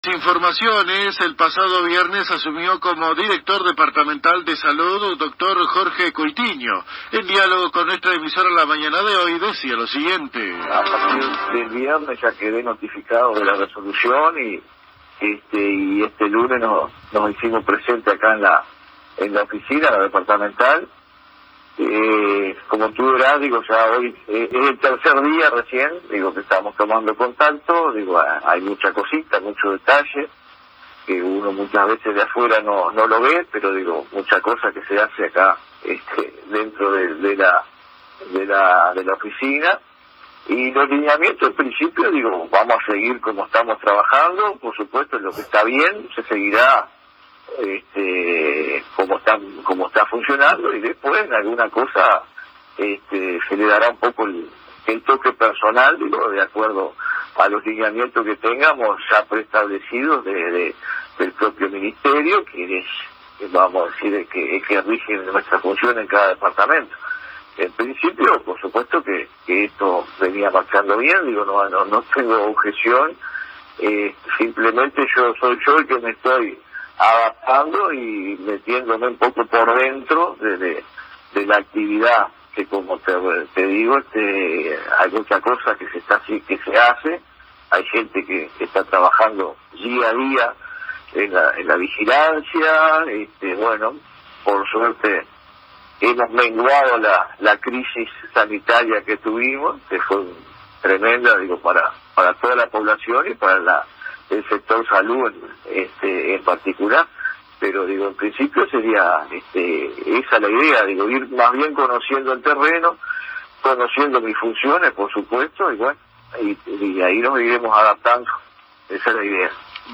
Los colegas de la AM 1110 local conversaron esta mañana con el Dr. Jorge Coitiño, nuevo Director Departamental de Salud, quien fue nombrado el pasado viernes en dicho cargo y apenas este lunes asumió las funciones de manera formal.